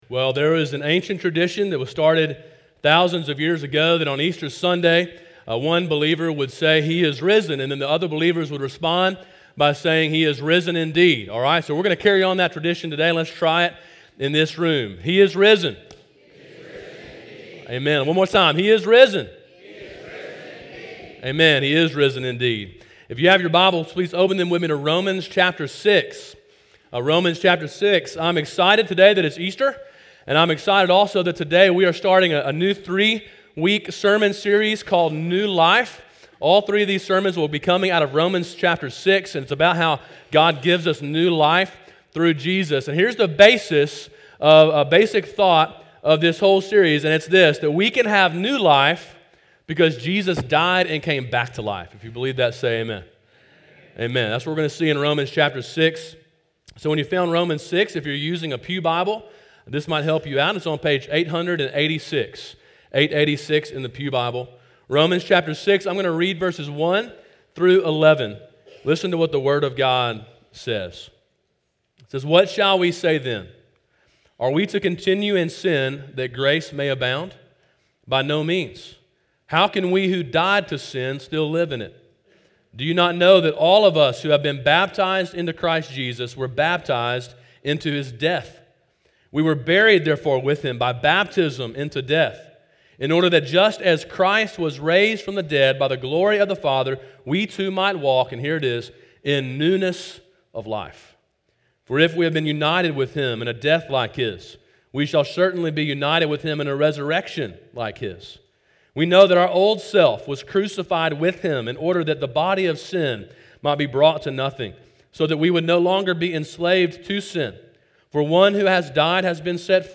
sermon4.21.19.mp3